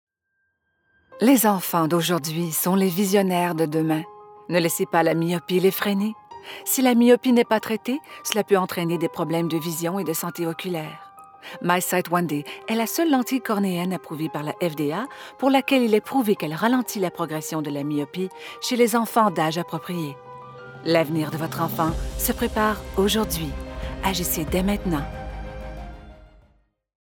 Vidéos explicatives
-Neumann TLM 103 Microphone
-Professional sound isolation recording booth